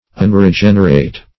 Unregenerate \Un`re*gen"er*ate\, Unregenerated